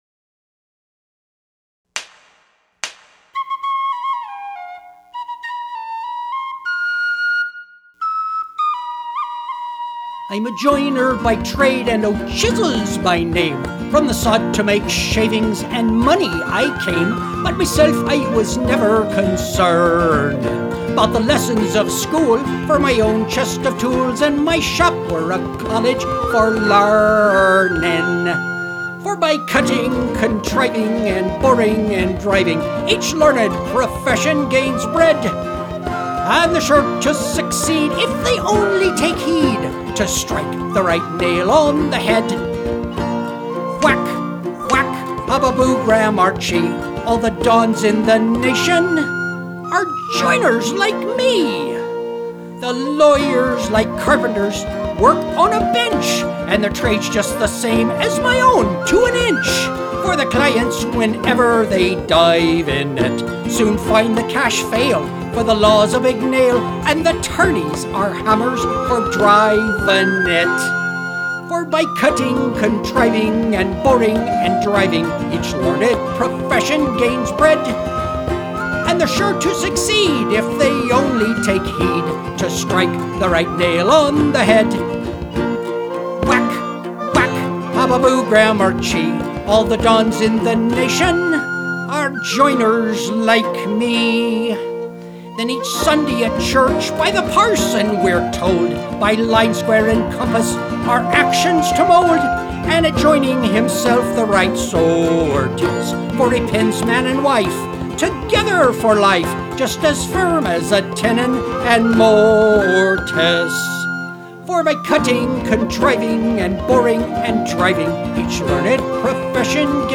octave mandolin and Irish whistle.
Irish whistle, guitar and Irish drum
I think it’s a perfect piece of shop music, and I am pondering a sing-along at the next woodworking event I attend.